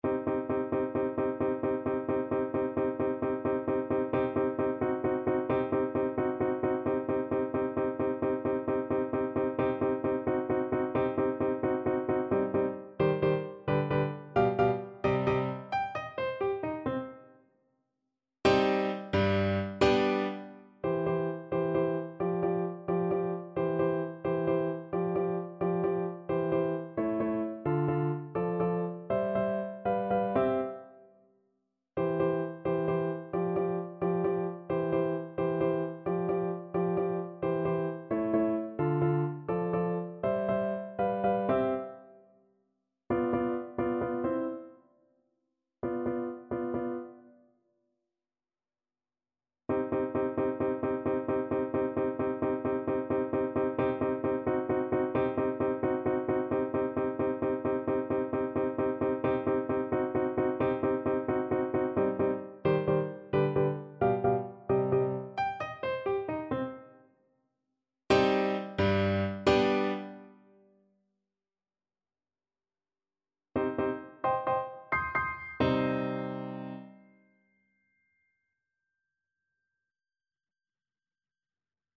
Play (or use space bar on your keyboard) Pause Music Playalong - Piano Accompaniment Playalong Band Accompaniment not yet available transpose reset tempo print settings full screen
C minor (Sounding Pitch) (View more C minor Music for Cello )
Allegro con brio (.=104) .=88 (View more music marked Allegro)
Classical (View more Classical Cello Music)